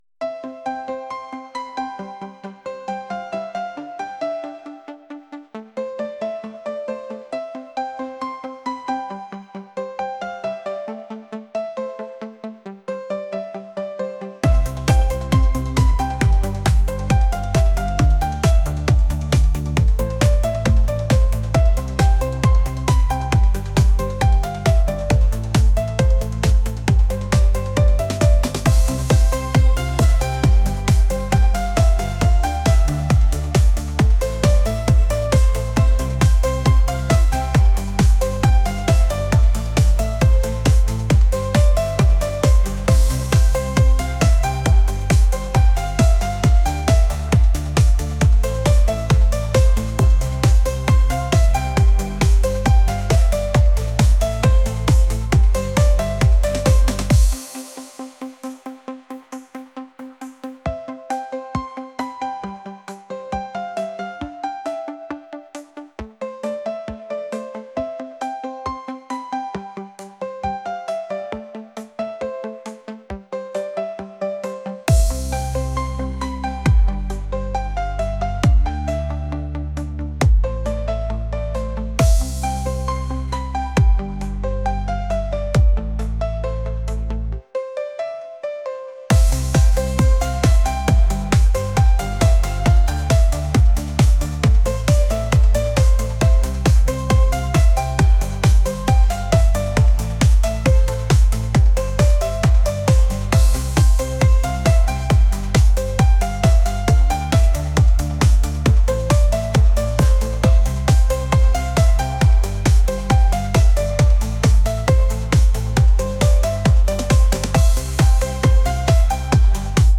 pop | electronic